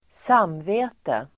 Uttal: [²s'am:ve:te]